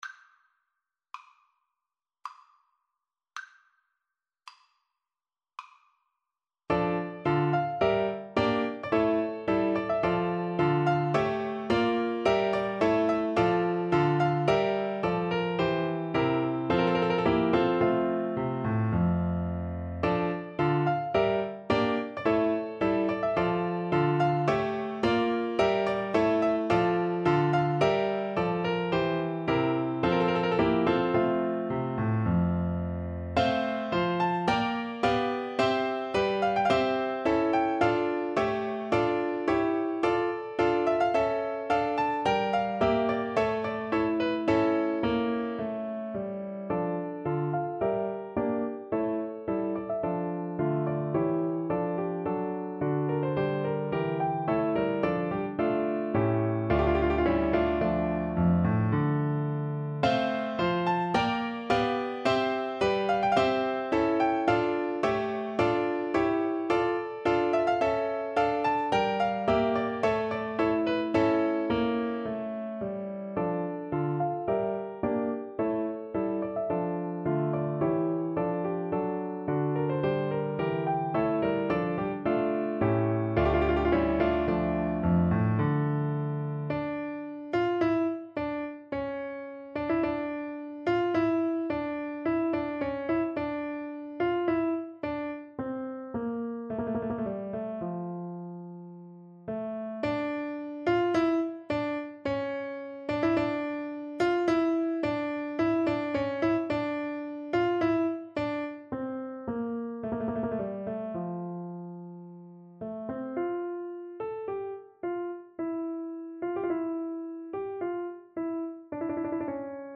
D minor (Sounding Pitch) A minor (French Horn in F) (View more D minor Music for French Horn )
3/4 (View more 3/4 Music)
~ = 54 Moderato
Classical (View more Classical French Horn Music)